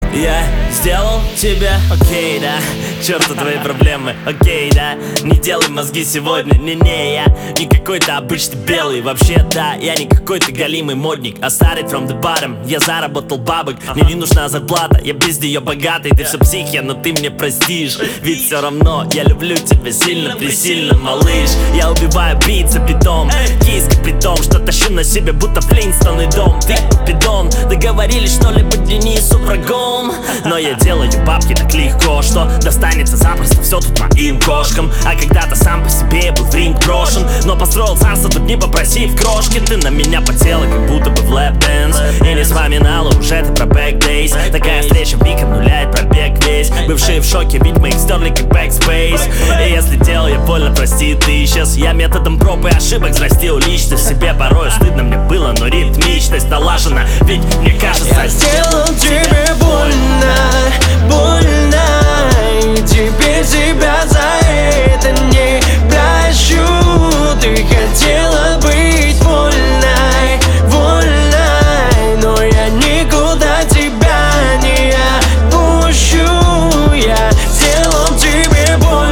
• Качество: 128, Stereo
рэп